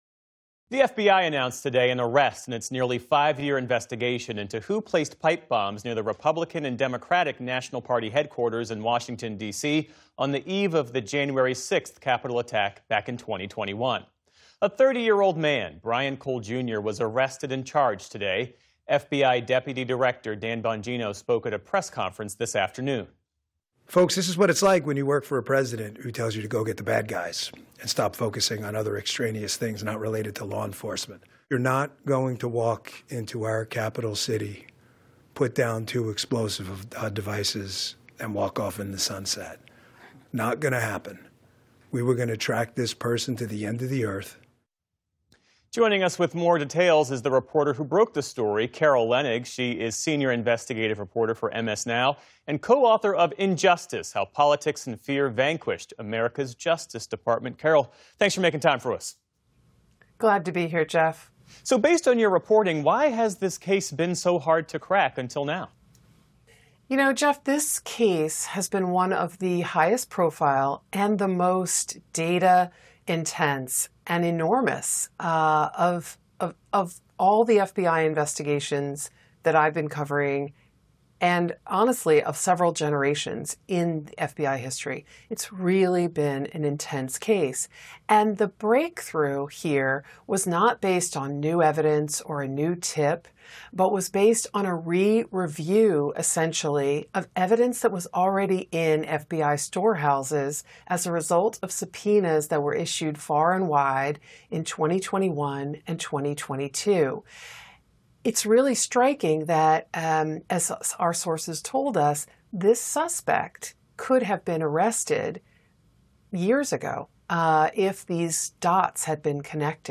Geoff Bennett discussed more with Carol Leonnig, the reporter who broke the story and co-author of “Injustice: How Politics and Fear Vanquished …